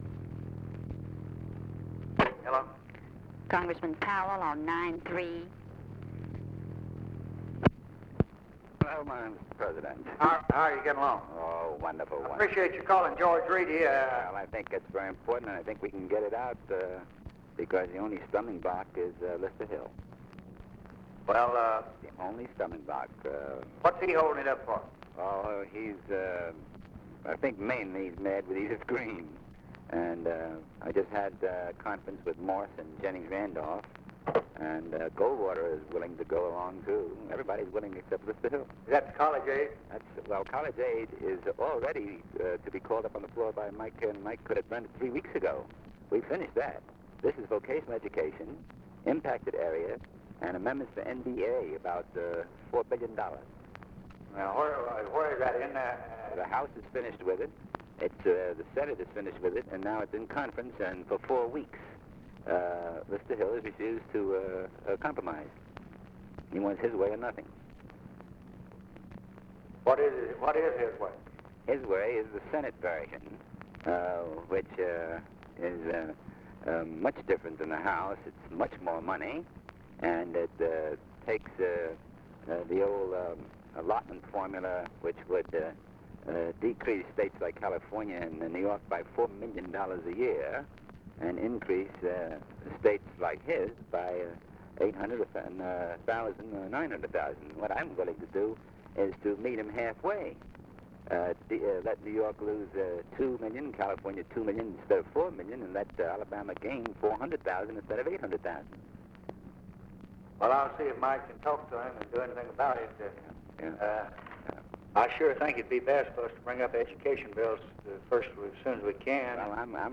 Conversation with ADAM CLAYTON POWELL, November 27, 1963
Secret White House Tapes